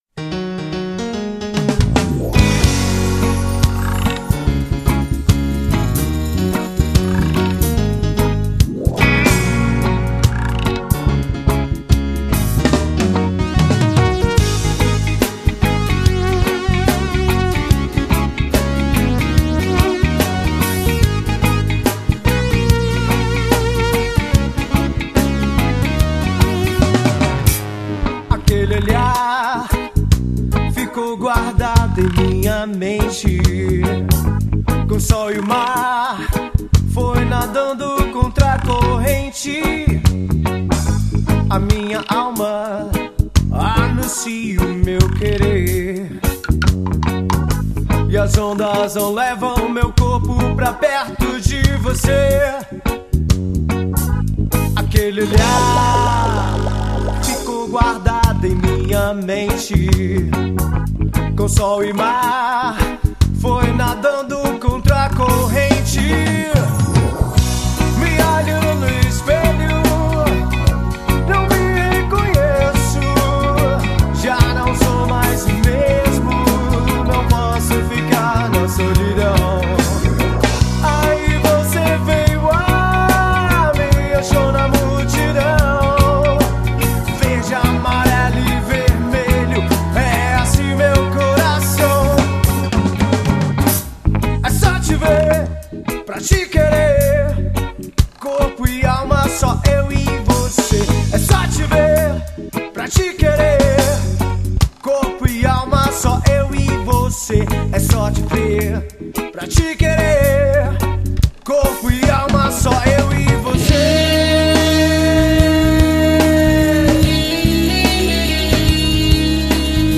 1971   03:56:00   Faixa:     Reggae